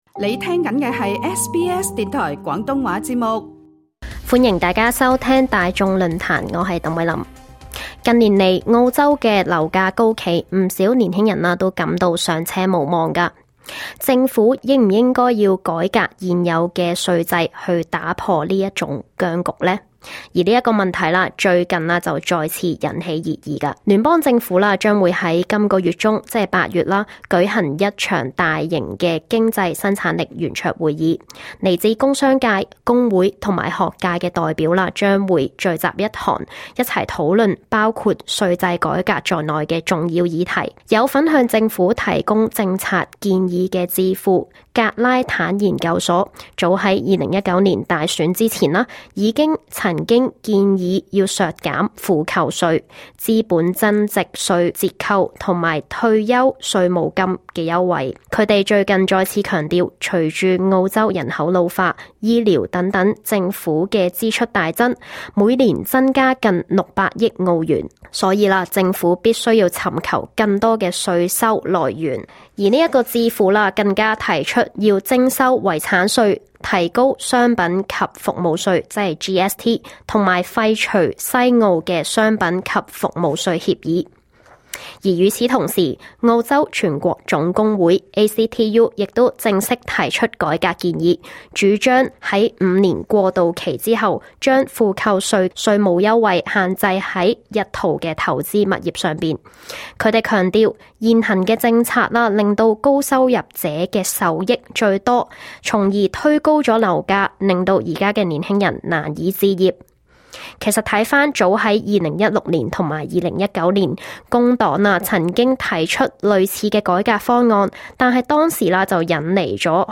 Source: Getty / Getty images SBS廣東話節目 View Podcast Series Follow and Subscribe Apple Podcasts YouTube Spotify Download (21.81MB) Download the SBS Audio app Available on iOS and Android 負扣稅制度再次成為澳洲政治與經濟改革的焦點。